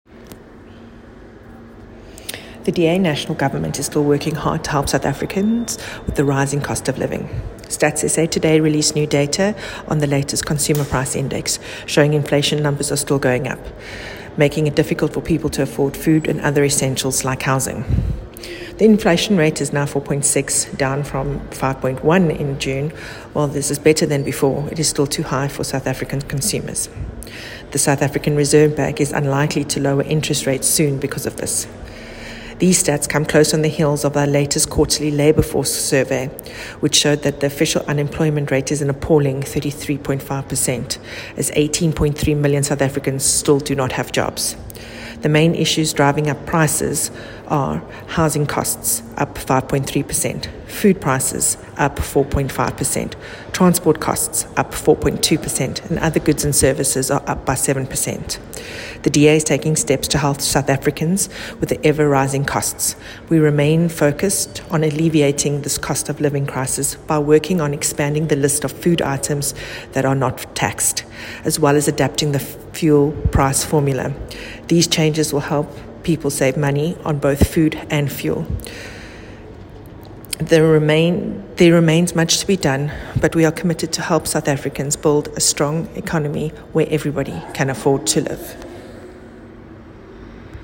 soundbite by Wendy Alexander MP